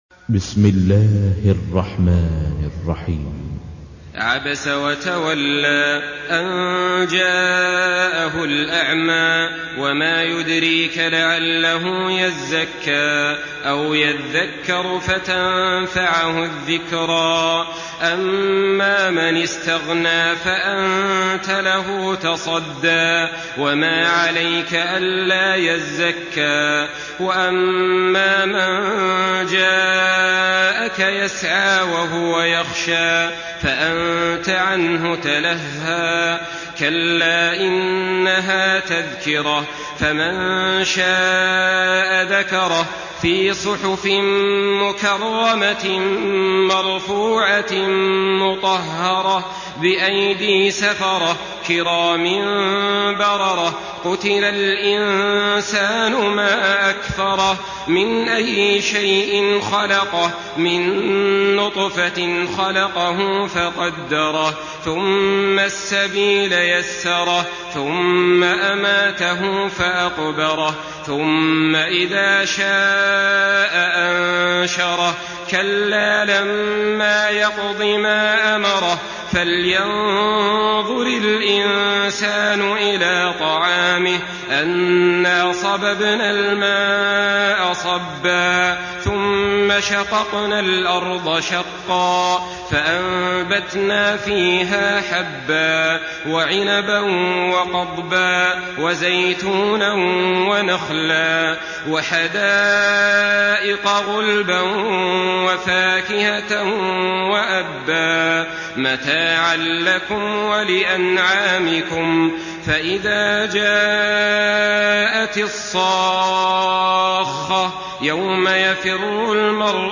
Surah عبس MP3 by صالح آل طالب in حفص عن عاصم narration.
مرتل